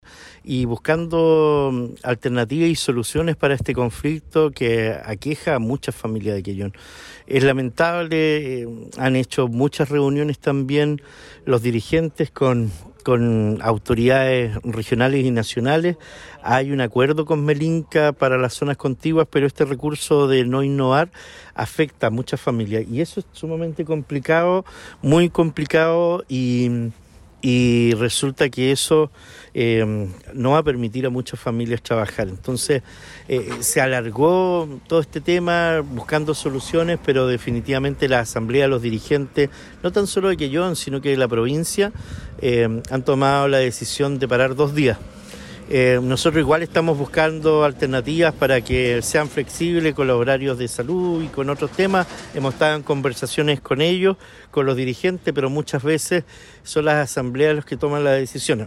El alcalde de Quellón Cristian Ojeda se mostró una vez más preocupado por el impacto que esta resolución judicial tiene en la comunidad, puesto que se rompe una cadena de valor que afecta a muchas personas y familias, causando un gran desmedro económico.